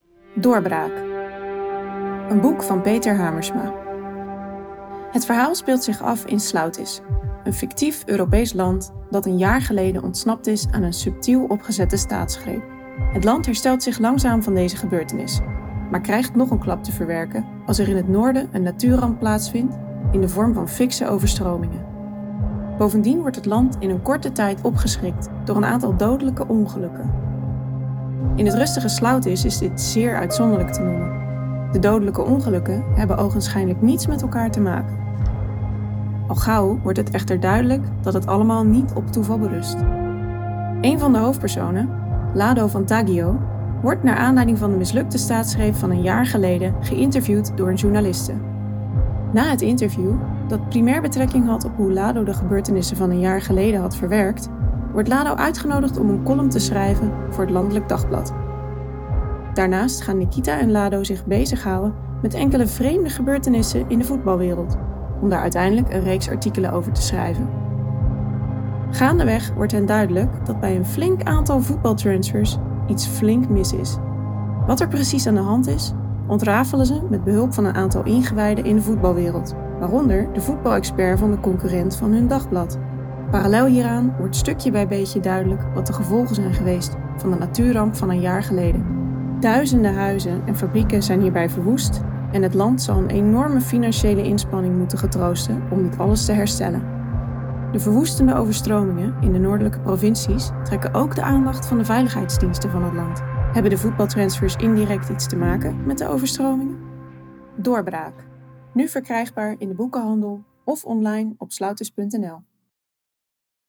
Ingelezen achterflap Doorbraak.
DoorbraakAudioTrailer-final.mp3